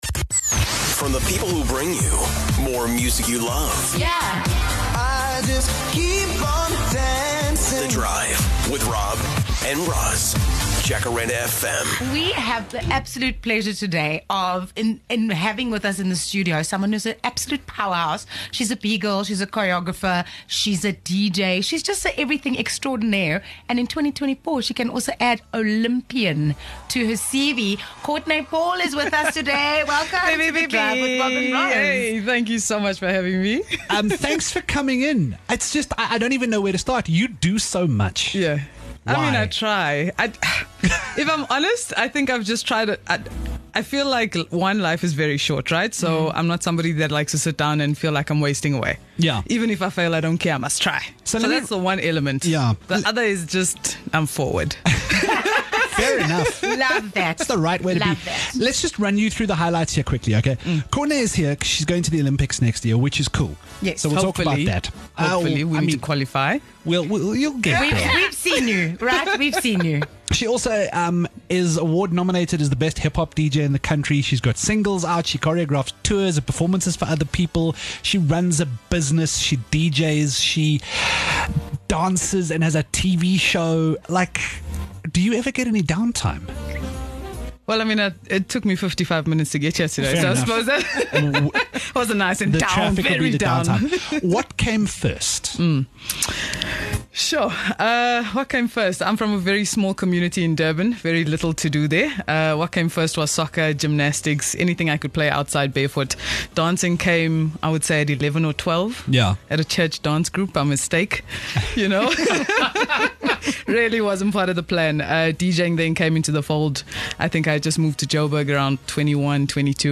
She joined us in studio to talk all about her journey and what it takes to become the best breakdancer on the continent.